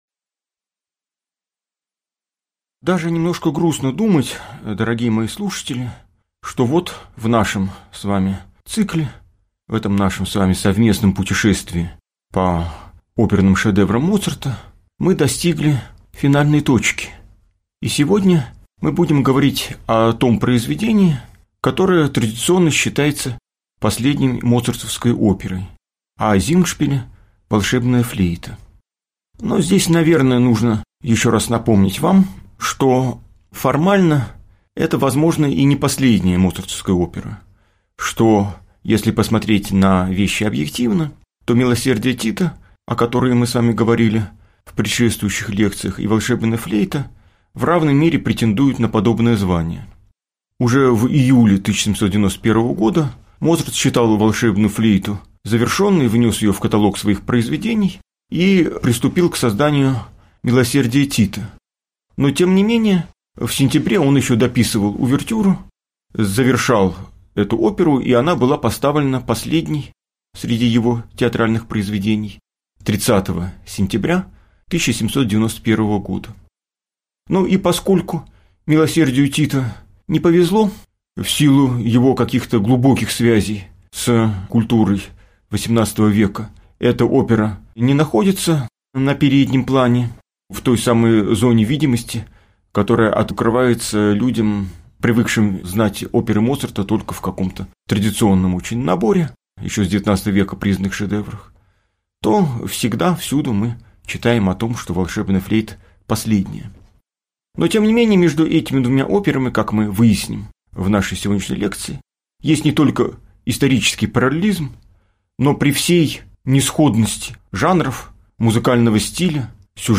Аудиокнига Лекция «Волшебная флейта». Бог-друг» | Библиотека аудиокниг